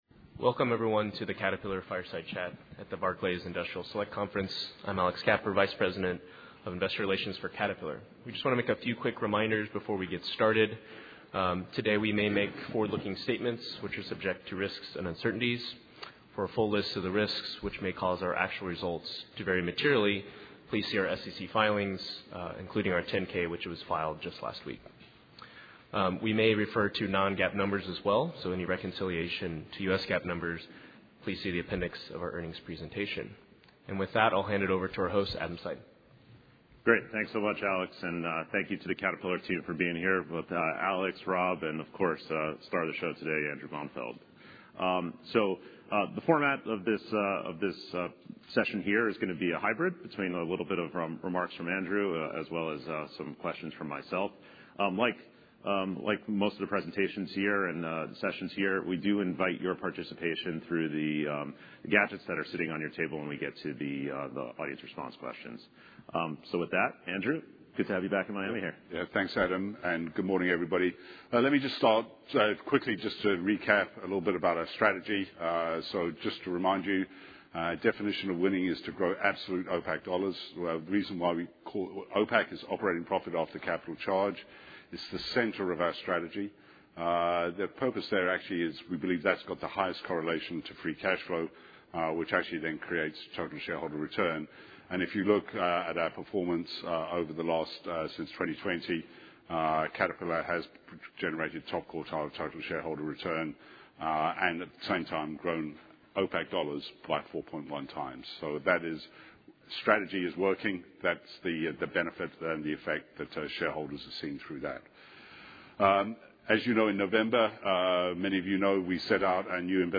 Caterpillar Fireside Chat at Barclays 43rd Annual Industrial Select Conference